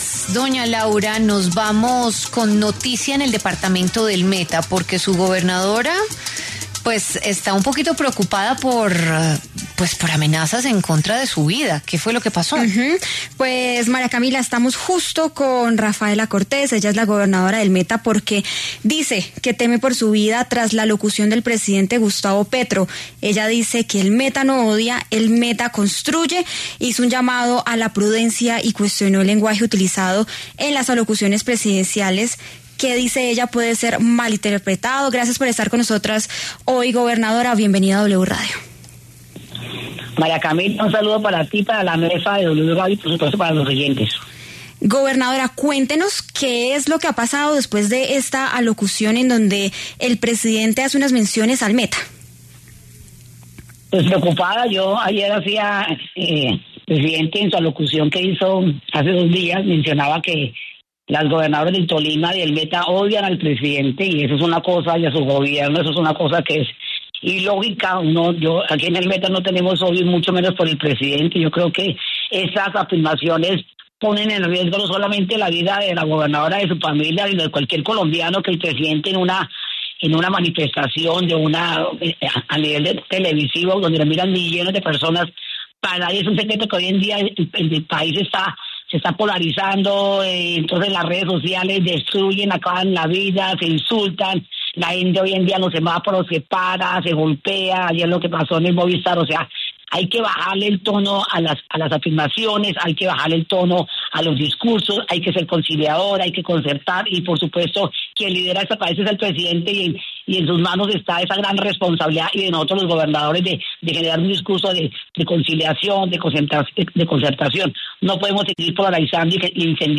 La gobernadora del Meta, Rafaela Cortés, habló en La W sobre las declaraciones de Gustavo Petro, quien afirmó que las Gobernaciones del Tolima y el Meta “odian al presidente y a su Gobierno”.